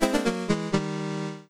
Jingles
GameOver2.wav